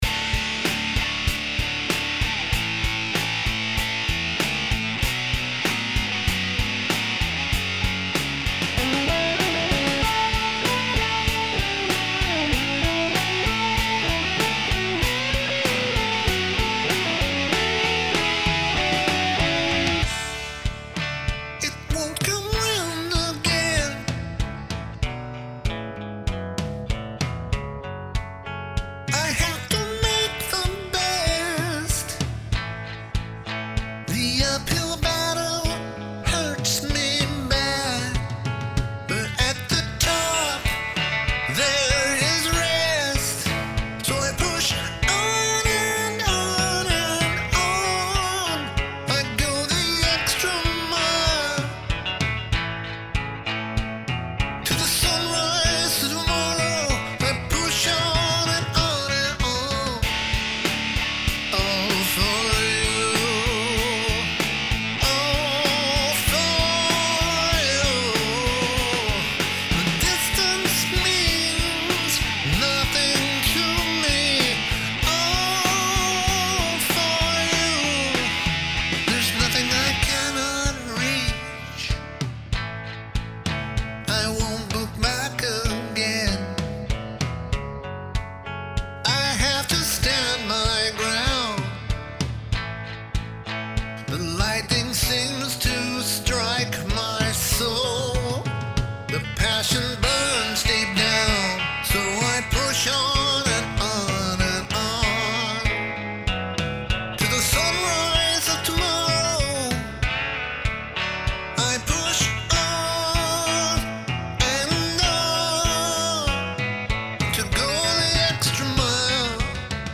Vocal Preformance